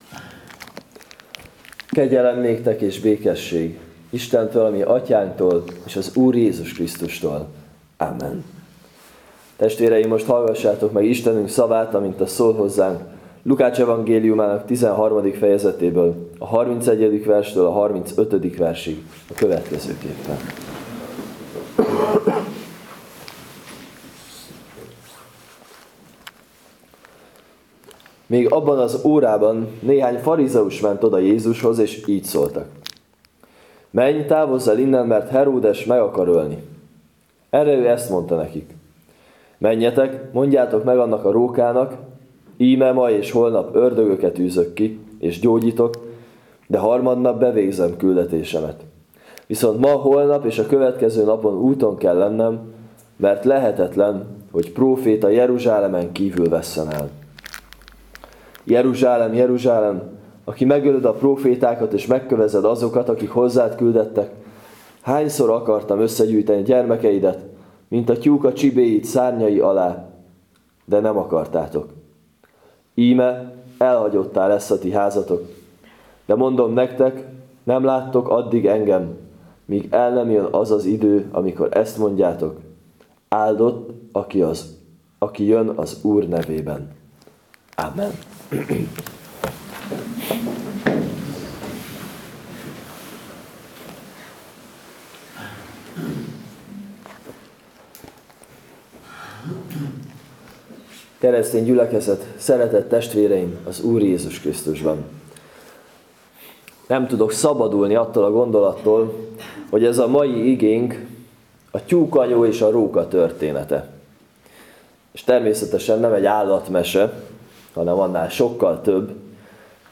02.04. Lk 13-31-35 igehirdetes.mp3 — Nagycserkeszi Evangélikus Egyházközség